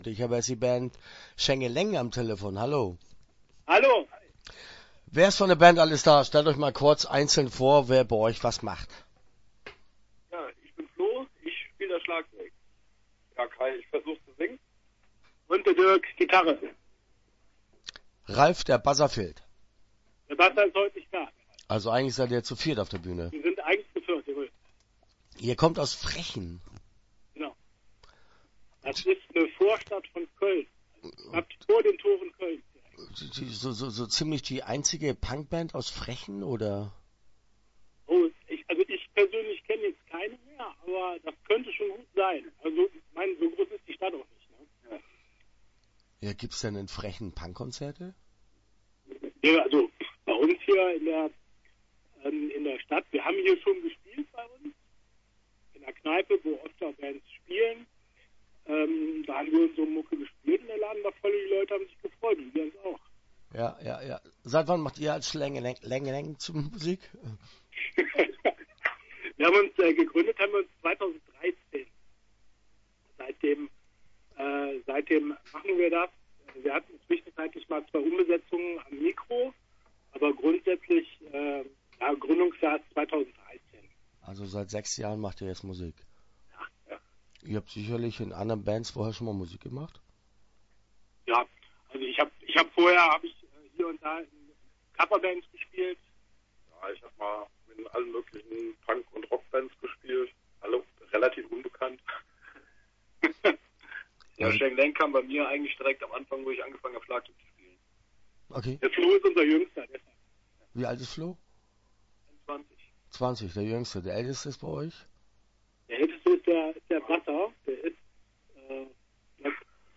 Start » Interviews » Schängeläng